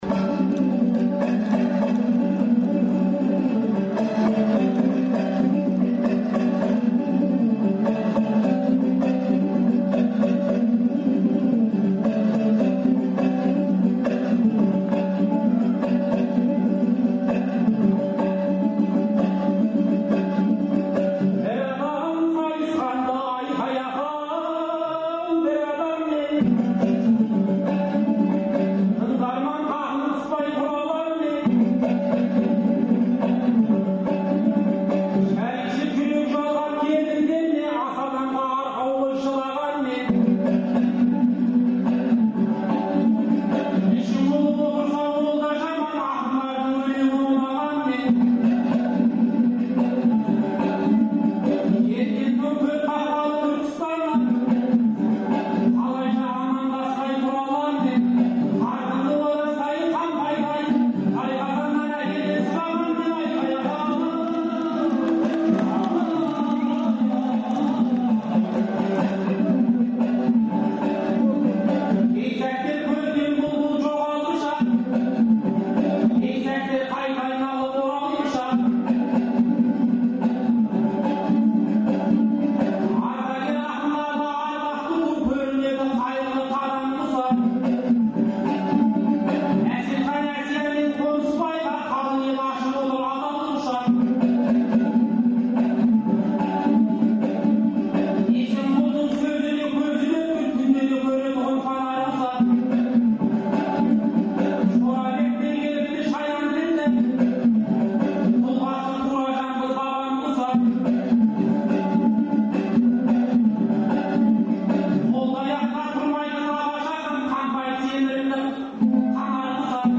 Республика сарайында өткен «Ретро айтыста»
қарсылас болды